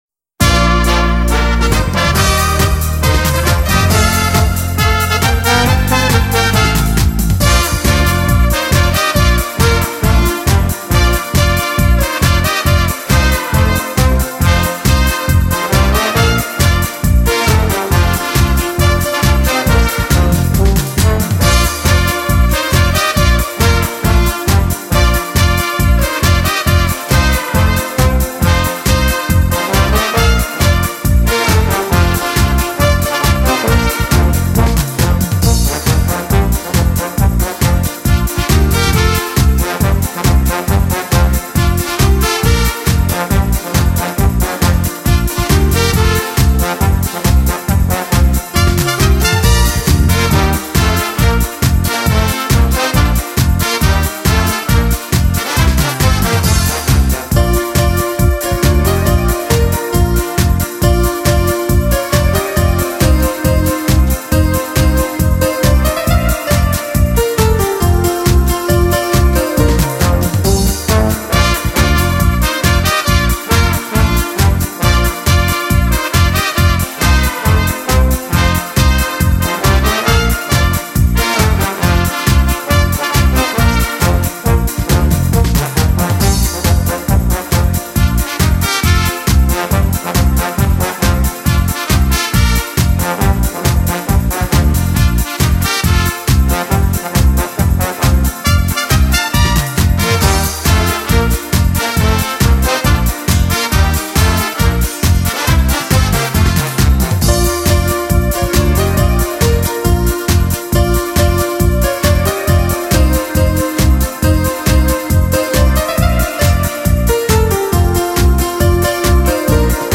Folclore Alemão.